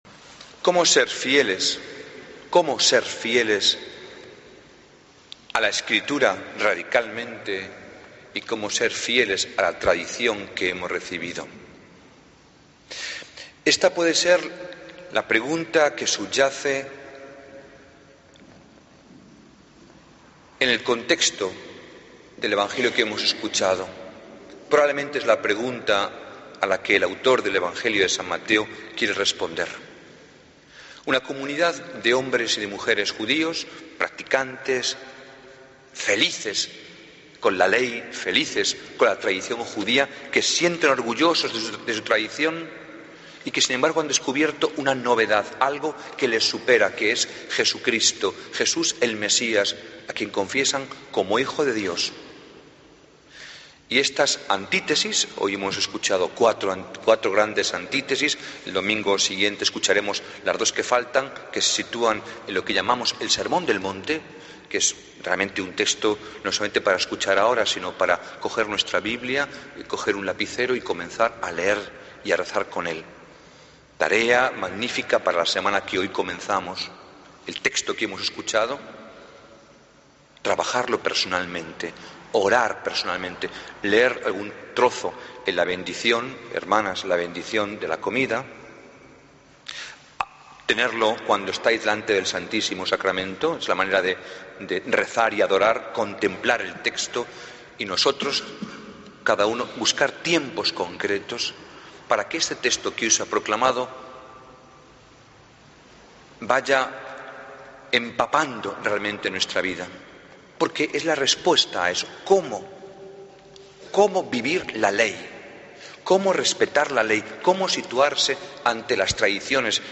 Homilía del Domingo 16 de Febrero de 2014